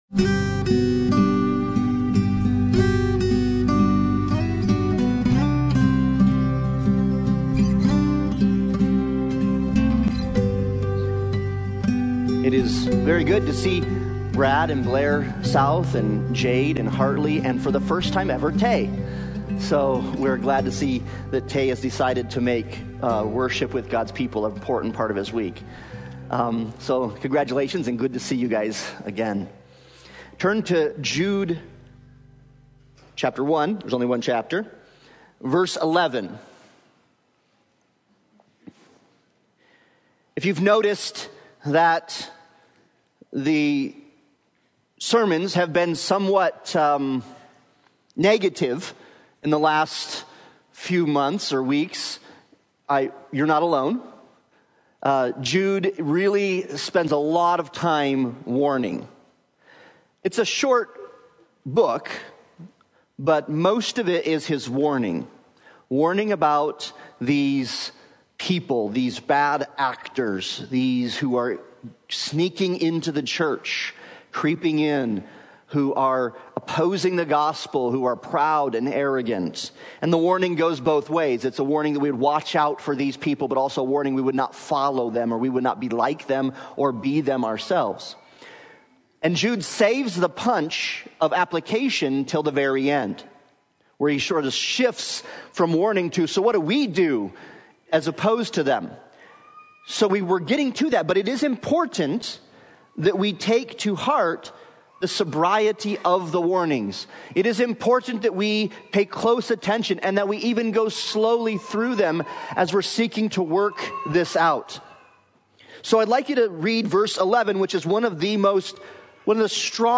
Jude 11 Service Type: Sunday Morning Worship « Ecclesiology